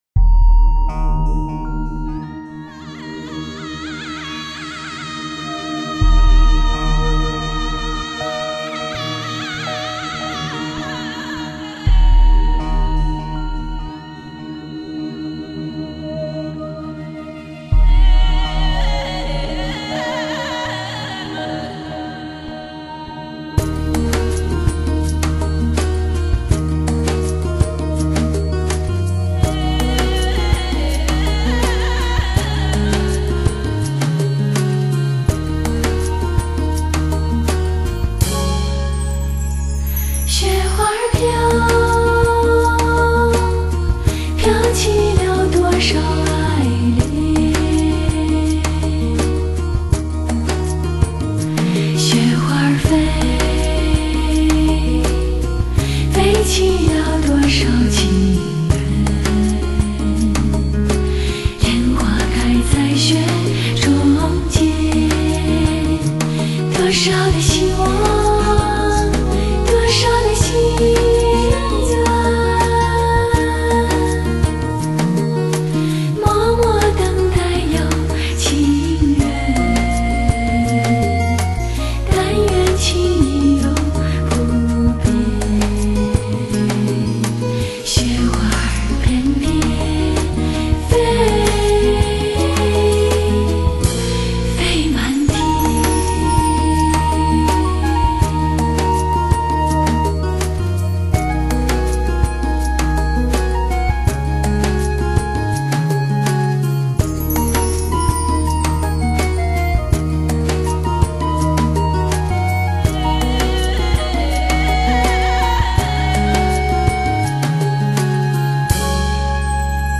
淡淡的感动、美好、梦一样的朦胧。